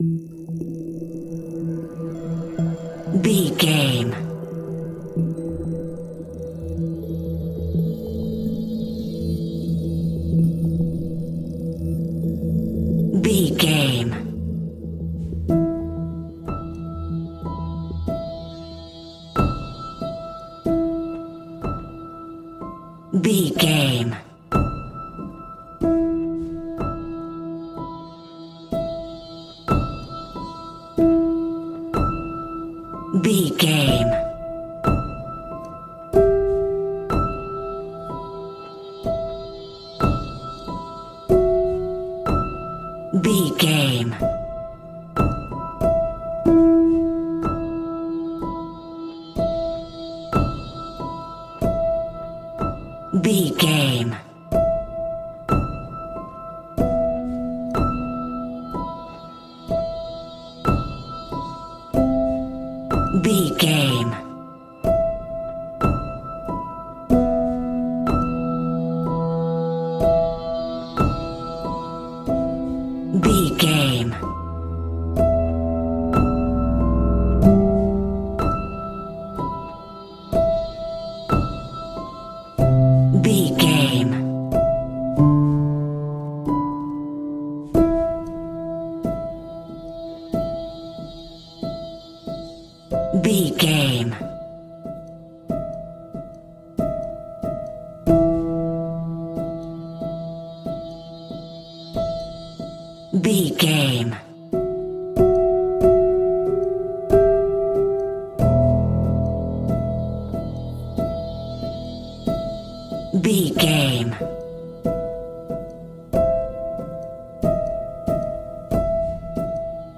Aeolian/Minor
tension
suspense
synthesiser